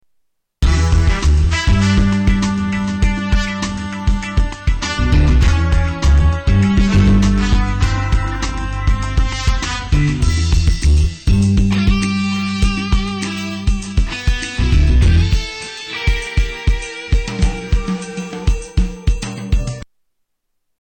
Category: Sound FX   Right: Personal
Tags: Sound effects Espionage music Espionage Stealth Music